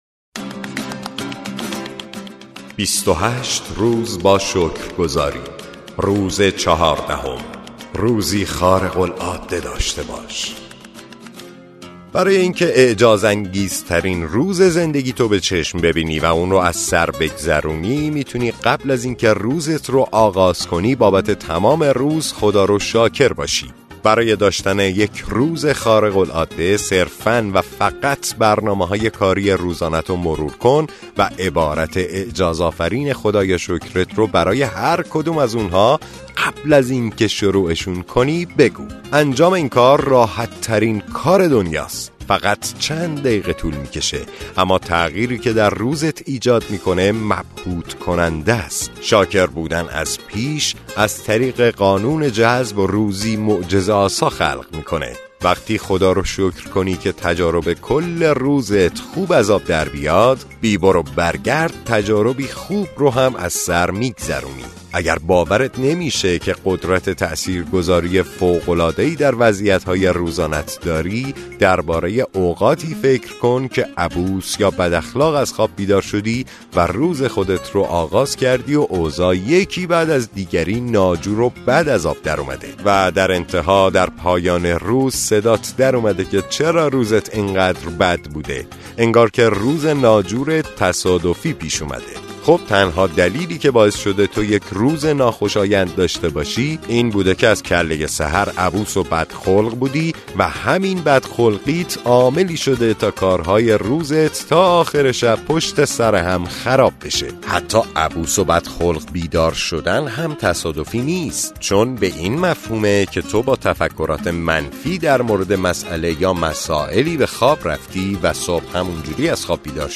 کتاب صوتی معجزه شکرگزاری – روز چهاردهم